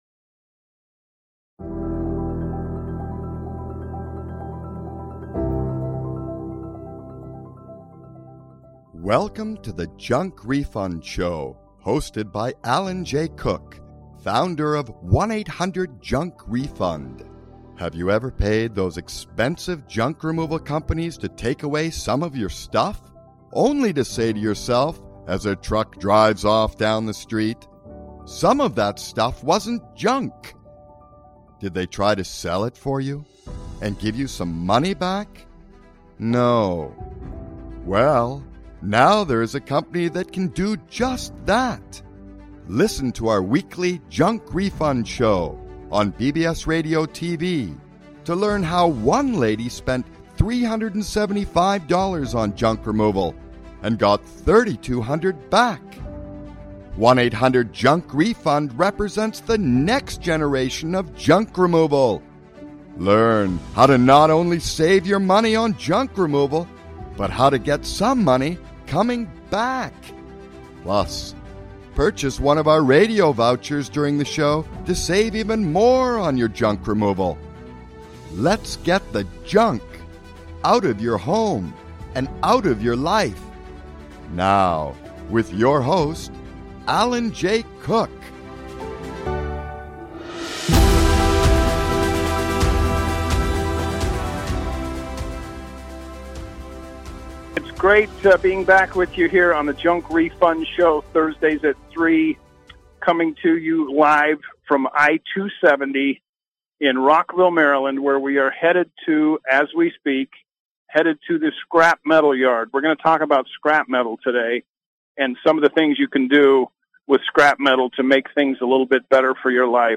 A Live Journey to the Junk Metal Scrap Yard where they purchase scrap metal.